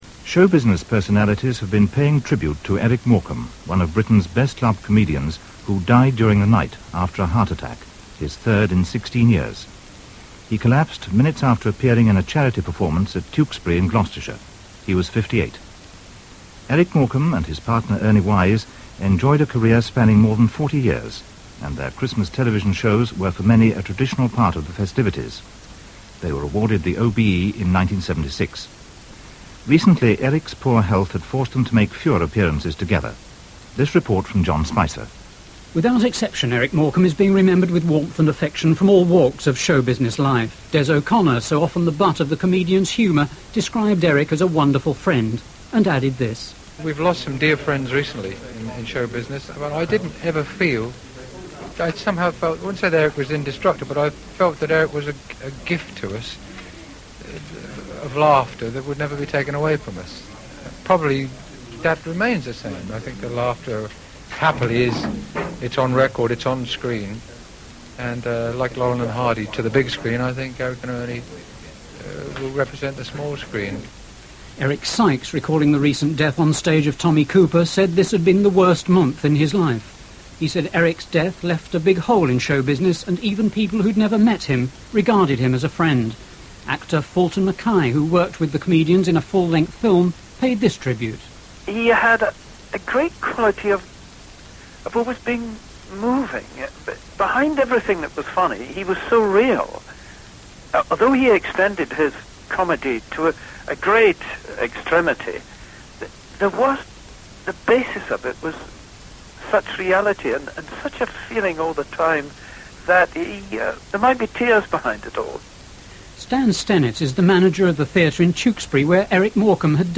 That partnership was abruptly ended in May 1984 when Eric Morecambe collapsed and died backstage at the Roses Theatre, Tewksbury. Here’s how BBC Radio 4 reported the news.